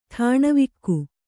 ♪ ṭhāṇavikku